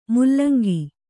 ♪ mullangi